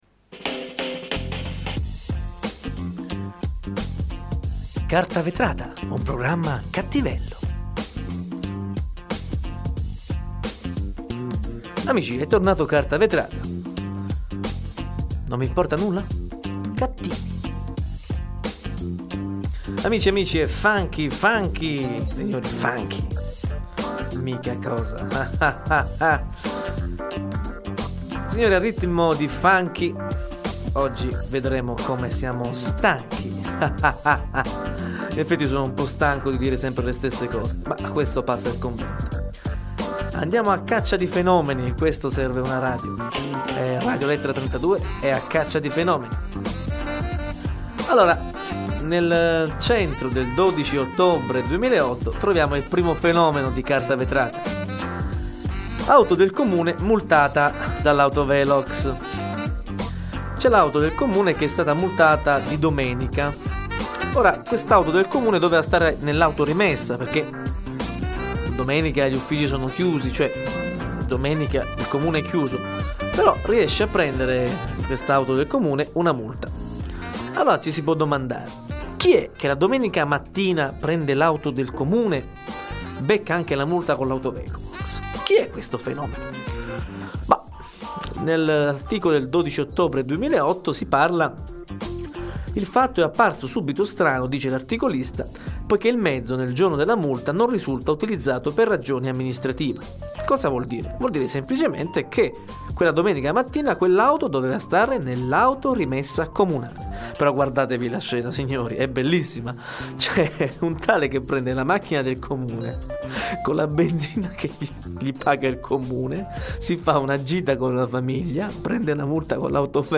Dedichiamo questa puntata della trasmissione radiofonica satirica "Carta Vetrata" a due fenomeni. Il primo fenomeno ha beccato, guidando un'auto comunale, una multa con l'autovelox una domenica mattina.